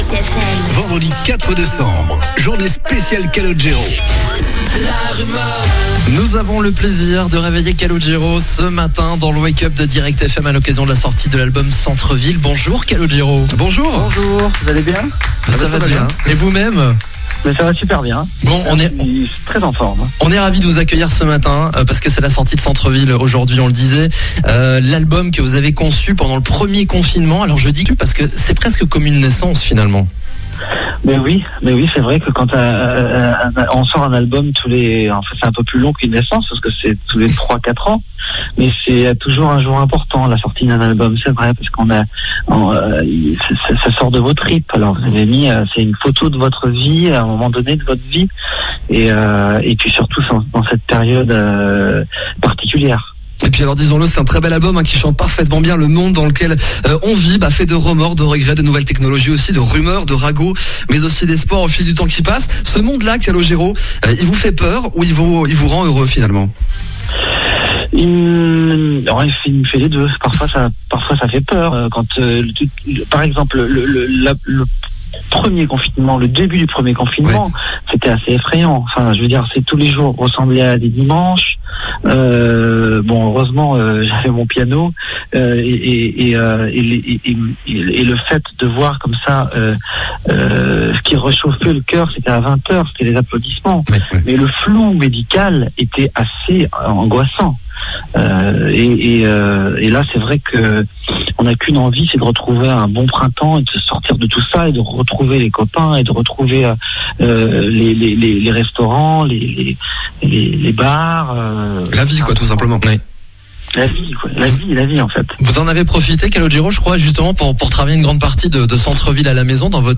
Interview-Calogero_WM.wav